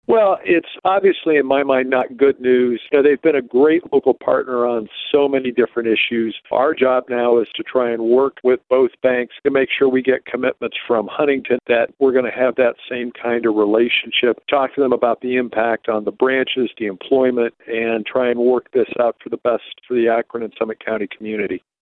Summit County Executive Russ Pry says he understands the business reasons for the merger, but is still disappointed about losing the FirstMerit corporate headquarters.